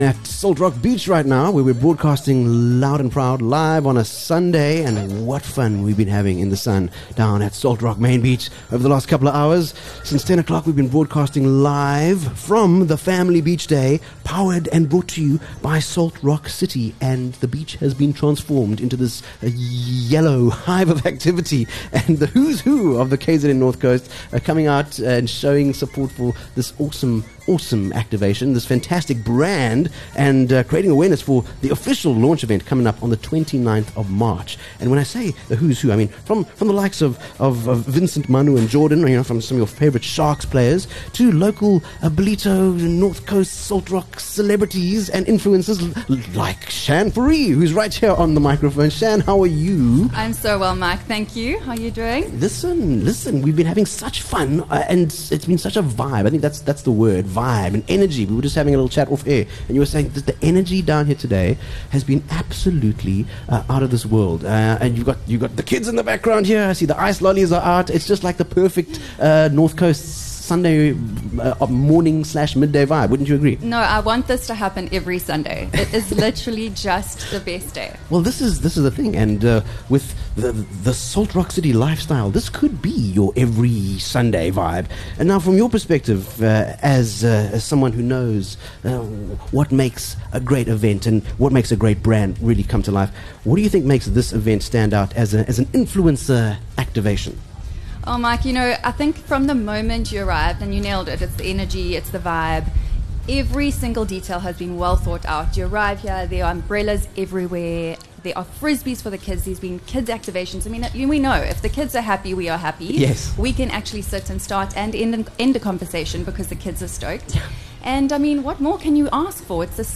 23 Feb Live from Salt Rock Main Beach: The Salt Rock City Family Beach Day